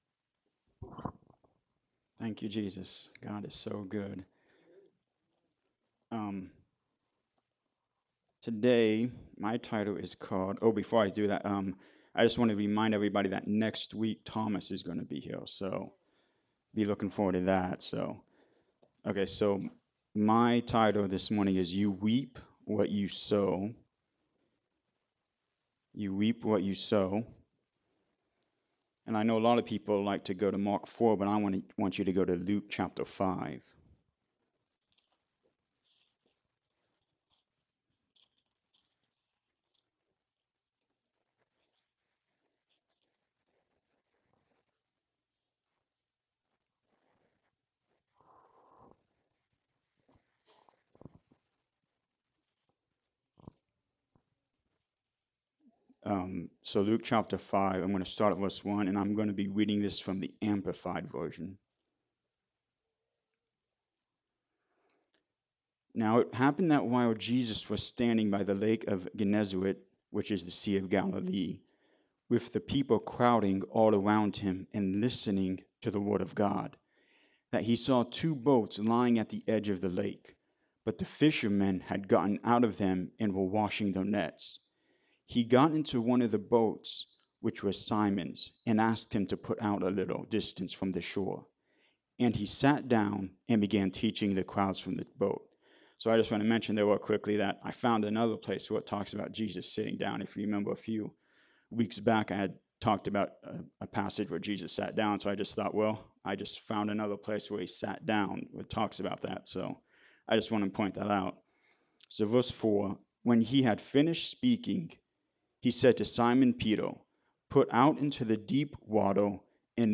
Luke 5:1-11 Service Type: Sunday Morning Service What are you reaping in your life right now?